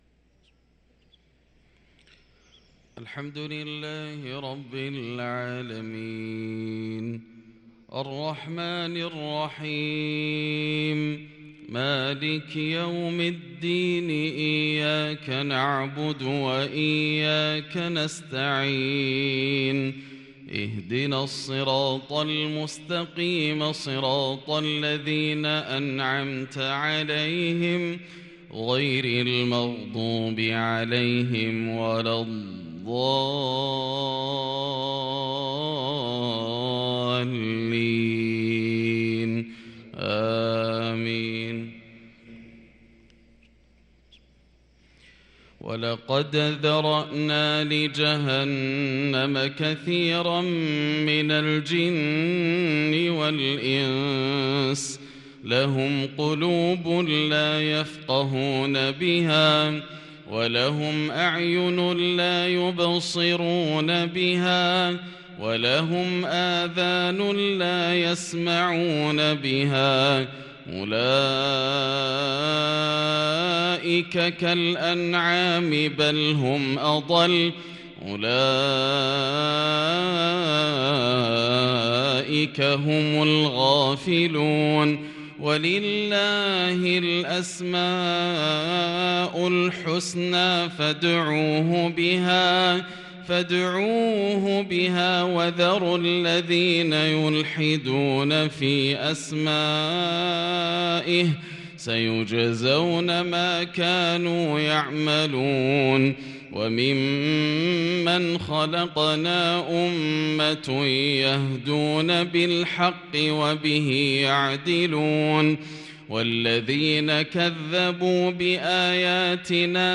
صلاة العشاء للقارئ عبدالرحمن السديس 2 جمادي الآخر 1444 هـ